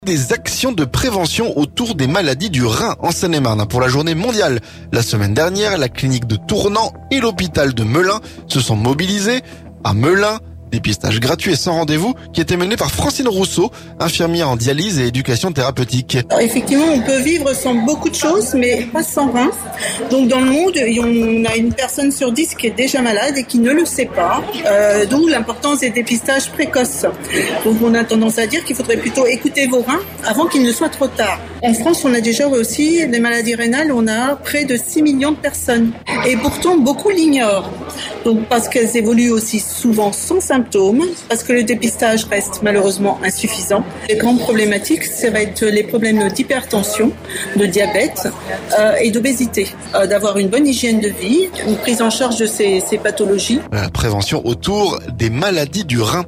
MELUN - Dépistage des maladies du rein à l'hôpital, notre reportage